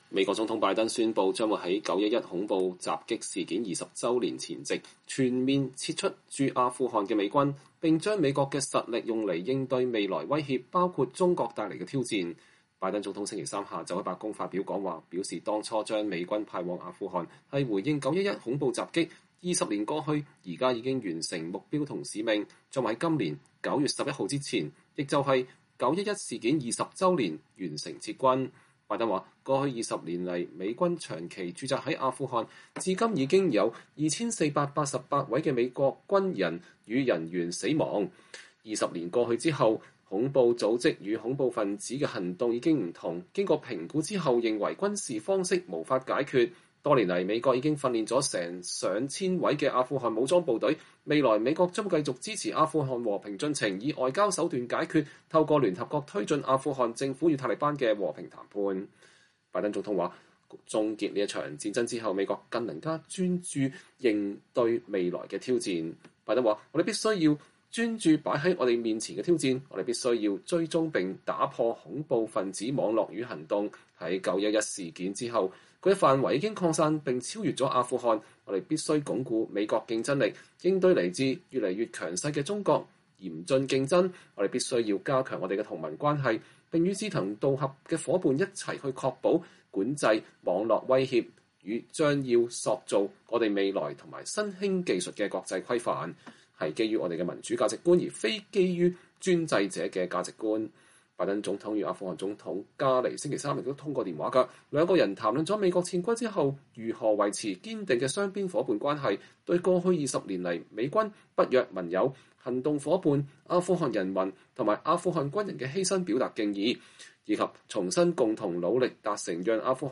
2021年4月14日拜登在白宮表講話，談美軍撤離阿富汗。
白宫 —